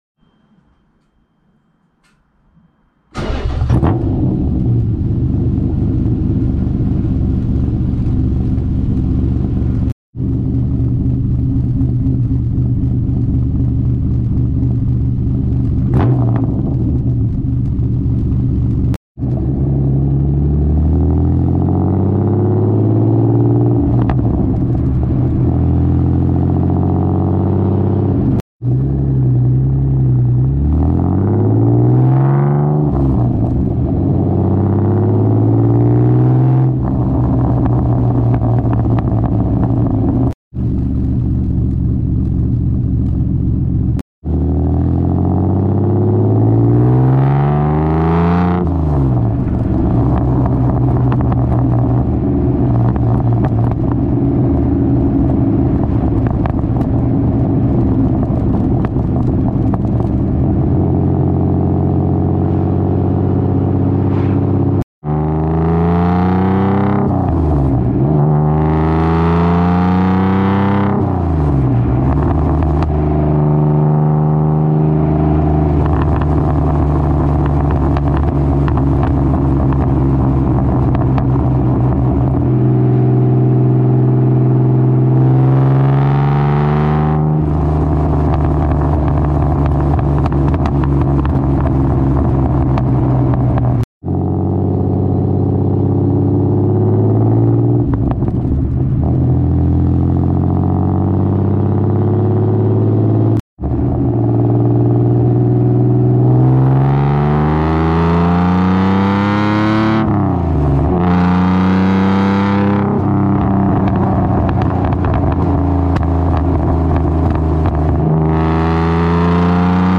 exhaust.mp3